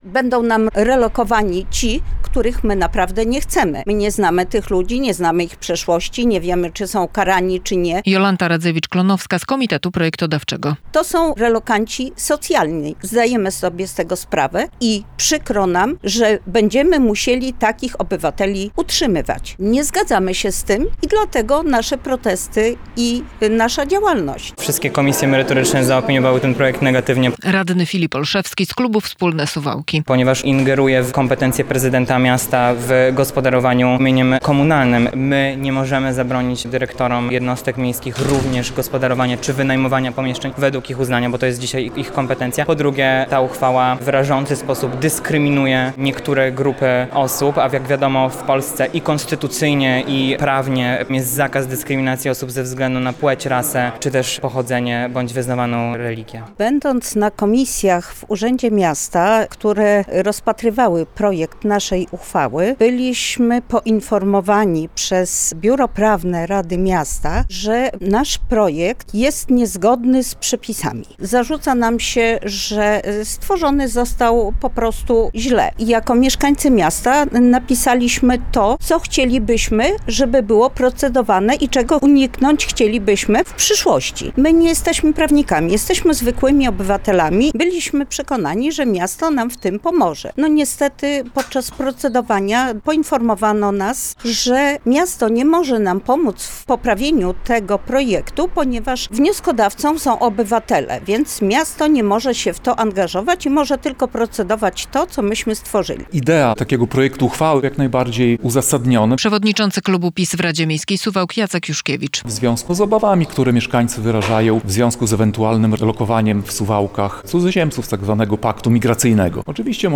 Sesja Rady Miejskiej Suwałk - relacja